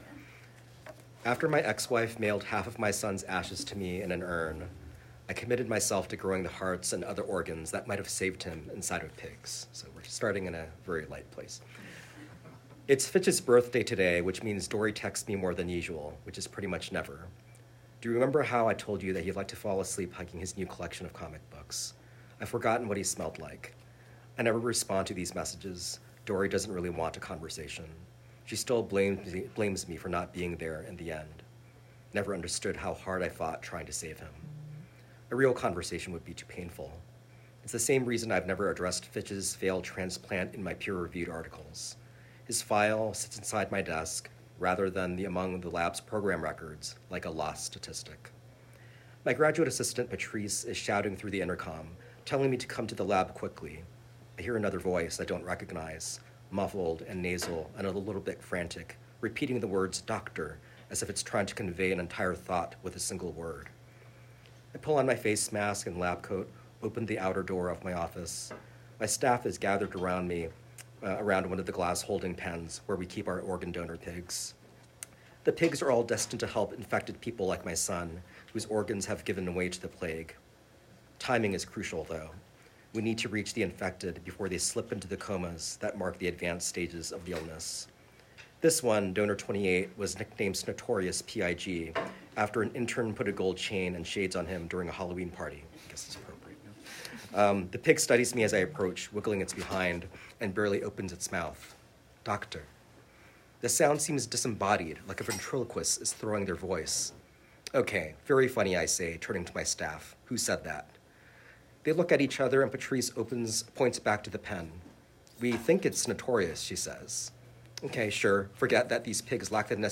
SEQUOIA NAGAMATSU: Should I start with a brief reading?
Sequoia-Nagamatsu-_Pig-Son_-reading.mp3